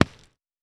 Ball Ground Impact.wav